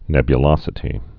(nĕbyə-lŏsĭ-tē)